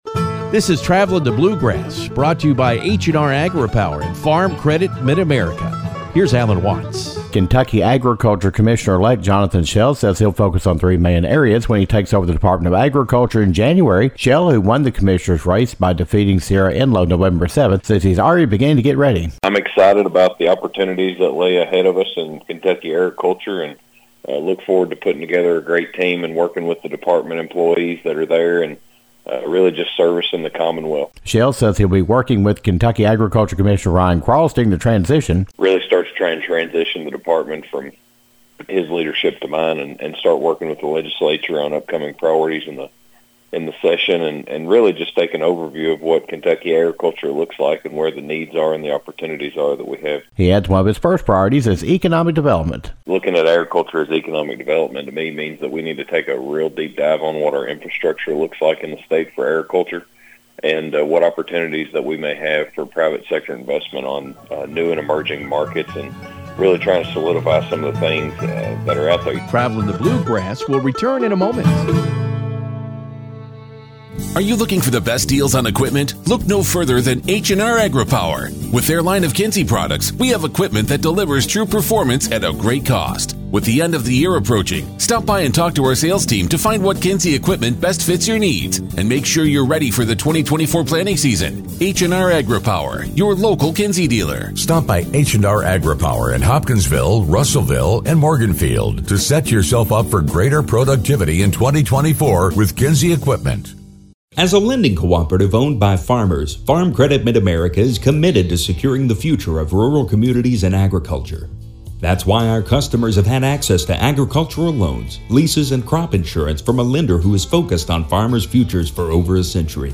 Newly elected Kentucky Agriculture Commissioner Jonathon Shell says he is ready to hit the ground running after he takes office in January. We talk with Shell about getting ready to take office, working with Commissioner Ryan Quarles, and his priorities for the Kentucky Department of Agriculture.